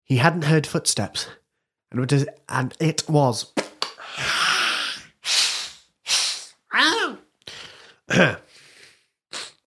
Sometimes even the best audiobook readers stumble a line or two.